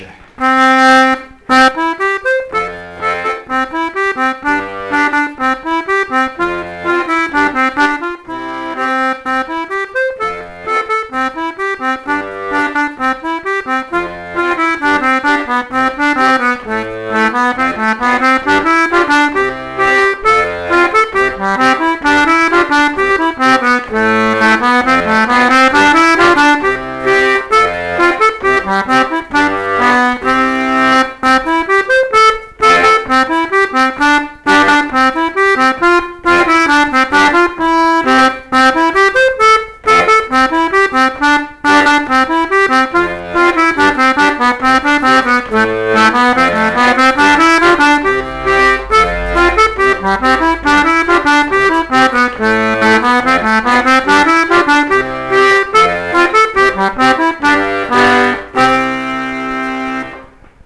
l'atelier d'accordéon diatonique